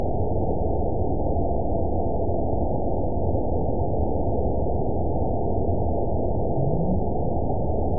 event 920383 date 03/20/24 time 22:44:55 GMT (1 year, 3 months ago) score 9.25 location TSS-AB03 detected by nrw target species NRW annotations +NRW Spectrogram: Frequency (kHz) vs. Time (s) audio not available .wav